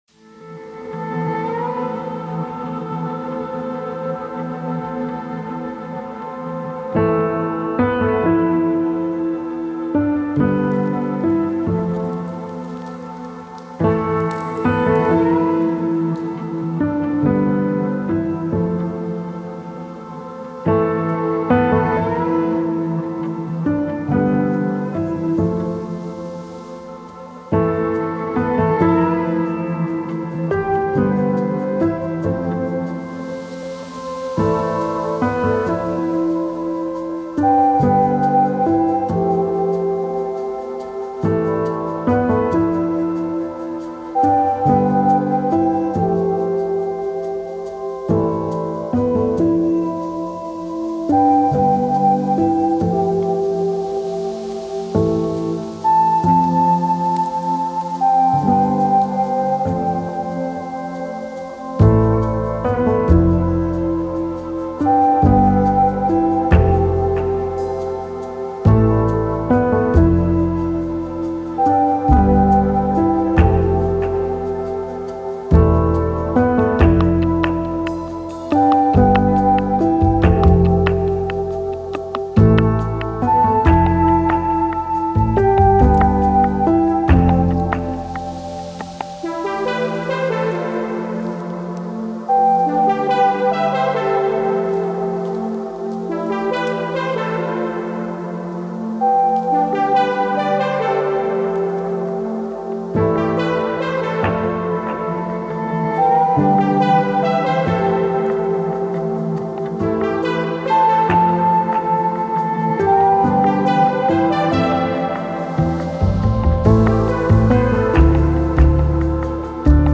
ambiance-spring-15770.mp3